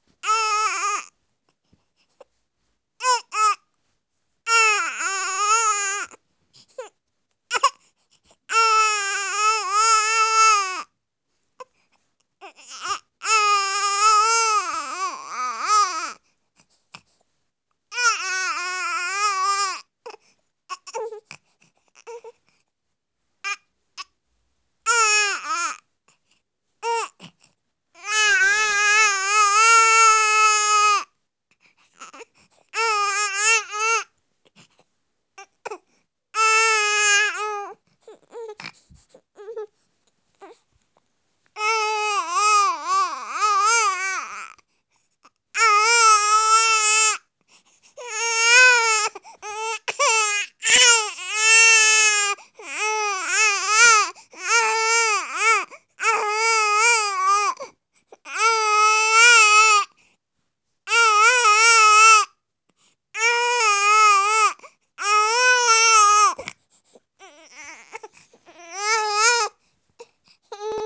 Infant_Cry_Speech_Data_by_Mobile_Phone